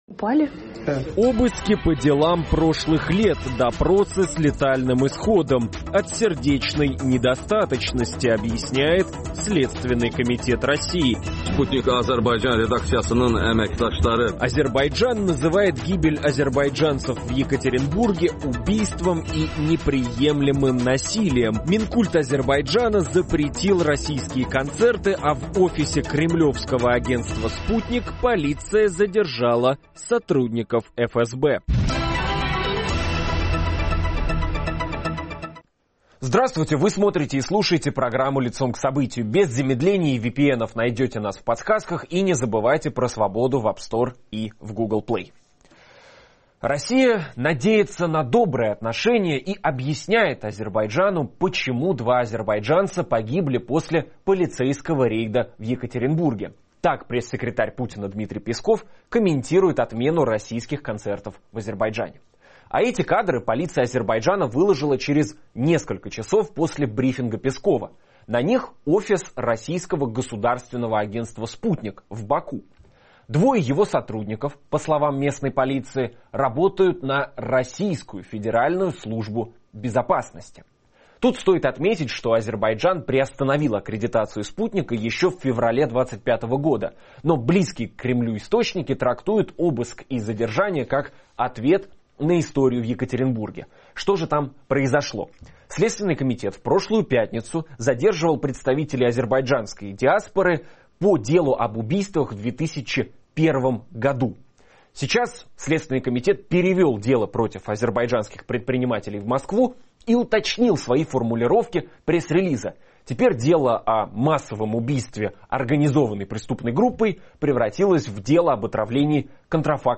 О конфликте Азербайджана и России и его возможных последствиях говорим с политологом